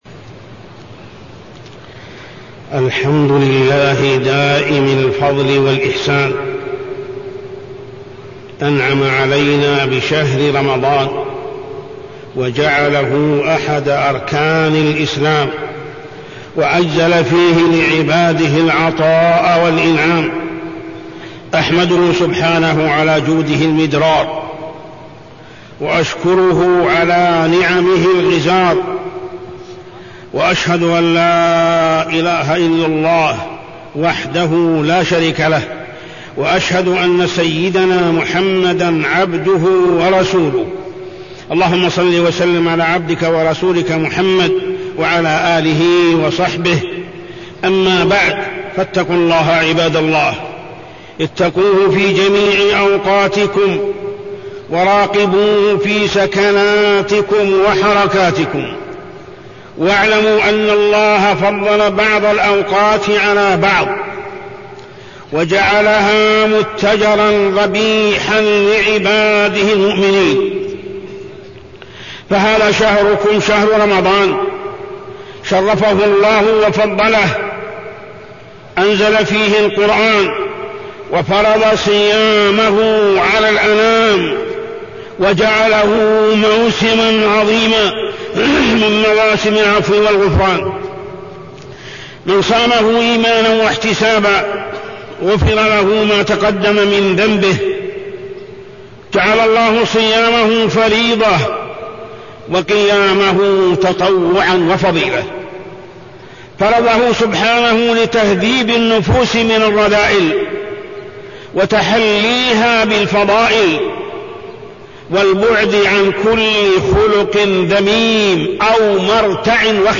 تاريخ النشر ٣ رمضان ١٤٢٣ هـ المكان: المسجد الحرام الشيخ: محمد بن عبد الله السبيل محمد بن عبد الله السبيل فريضة الصيام